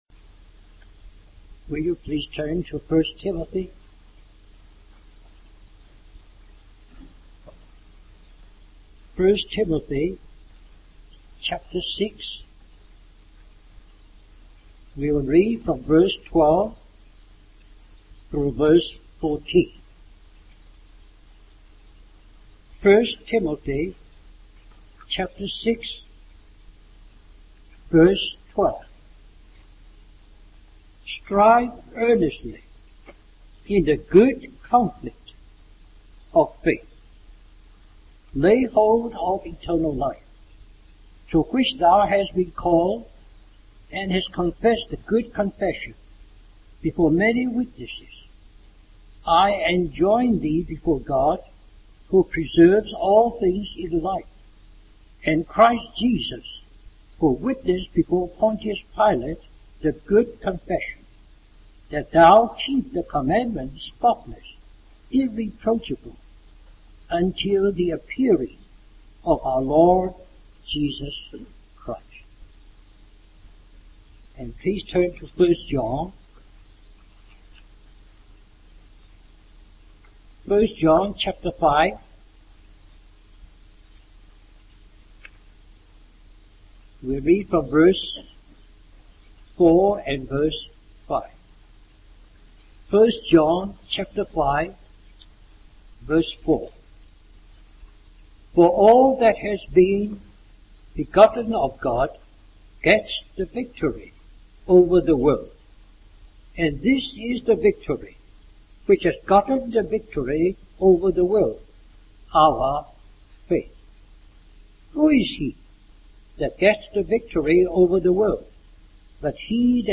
Western Christian Conference We apologize for the poor quality audio
(please note the recording quality of this messge is poor)